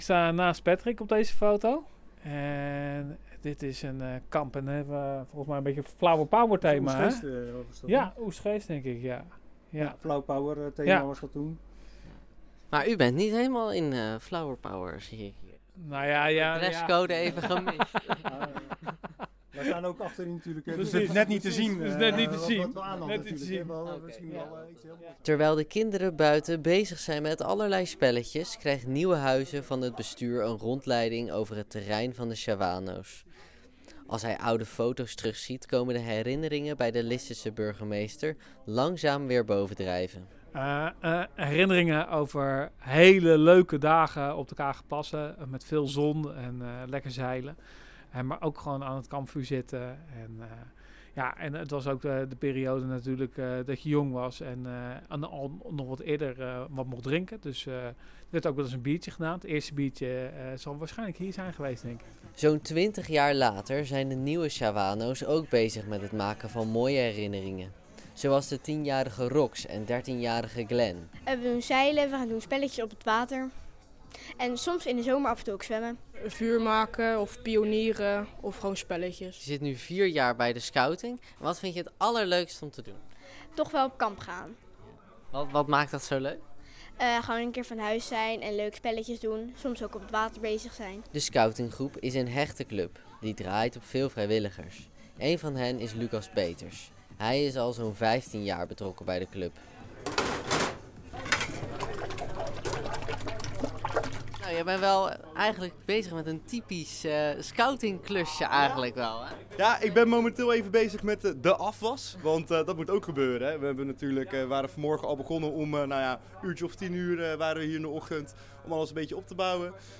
Zaterdag 13 september werd op het terrein van de vereniging de jaarlijkse zomerstunt georganiseerd. Een open dag voor iedereen om kennis te maken met de vereniging.
reportage